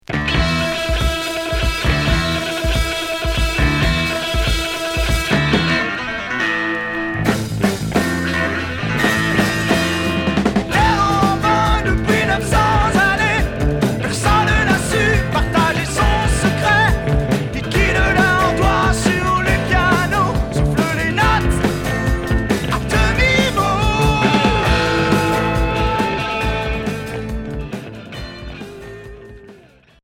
Heavy pop progressif Deuxième 45t retour à l'accueil